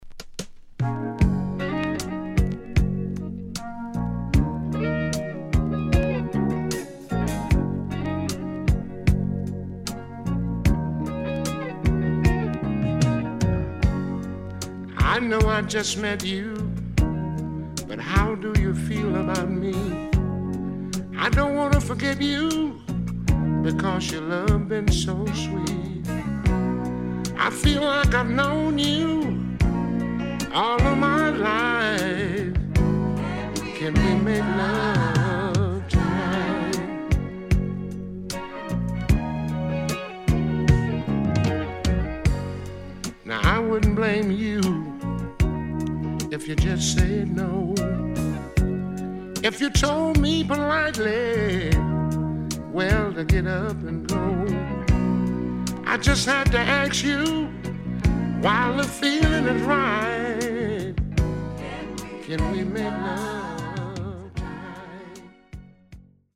HOME > Back Order [SOUL / OTHERS]